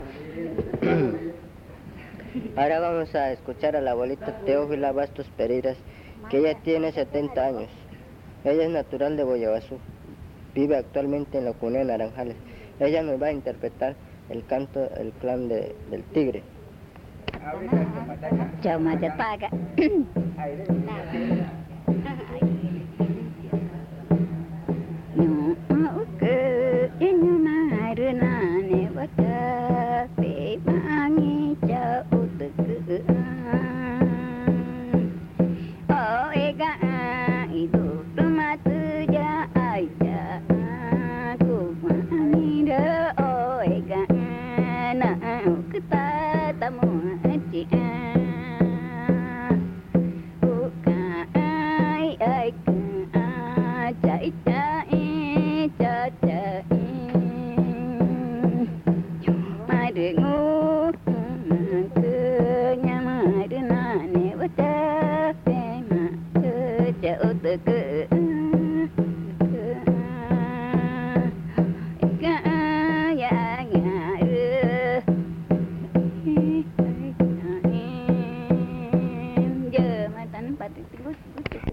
Canto del clan Tigre
Pozo Redondo, Amazonas (Colombia)
La abuela usa tambor mientras canta.
The elder uses a drum while singing.